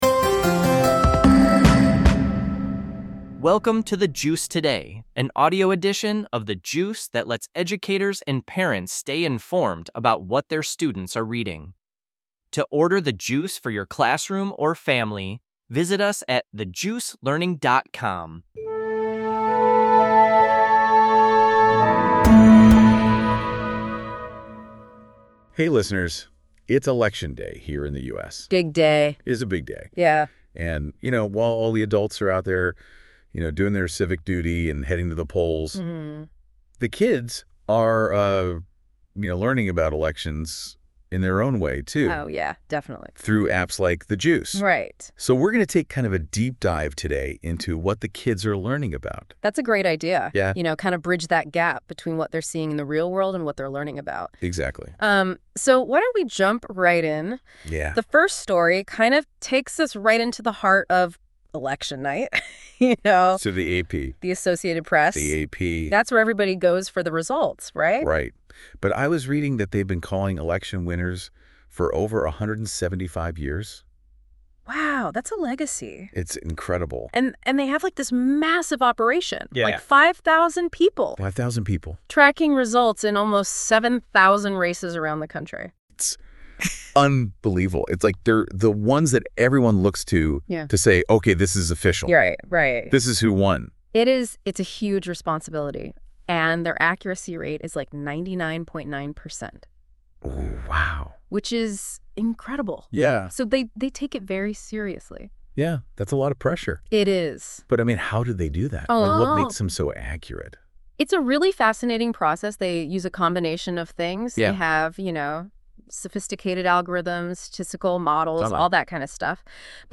Why is Electioneering Prohibited.Visit Us OnlineThe Juice Learning (for Educators) The Juice Today (for Parents)Production NotesThis podcast is produced by AI based on the content of a specific episode of The Juice.